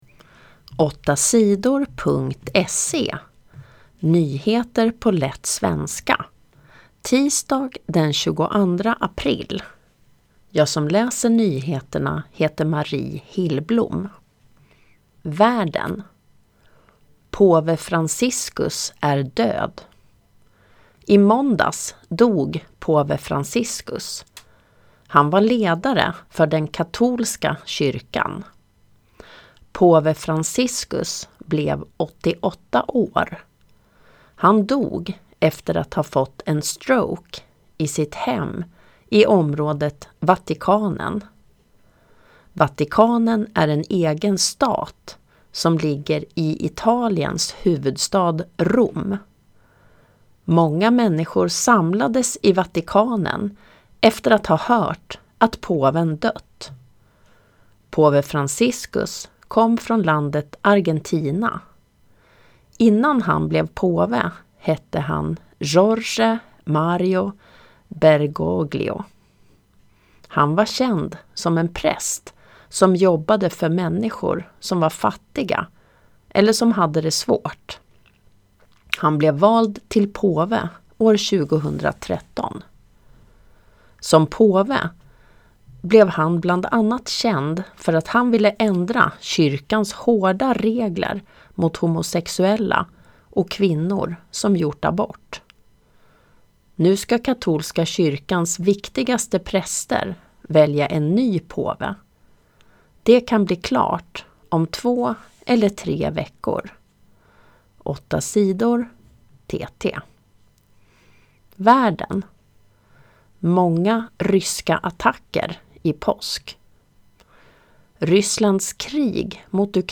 - lättlästa nyheter … continue reading 32 episodes # Lyssna 8 Sidor # News Talk # Nyheter # 8 Sidor